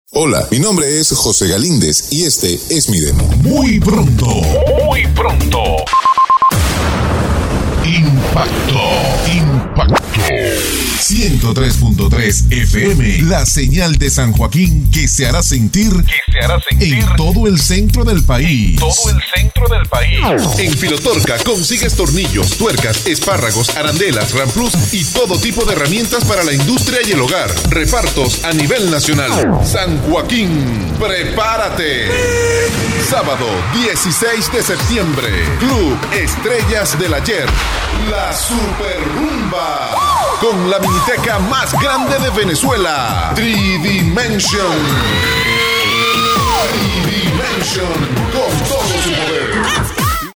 VOZ COMERCIAL
spanisch Südamerika
Sprechprobe: Werbung (Muttersprache):
COMMERCIAL VOICE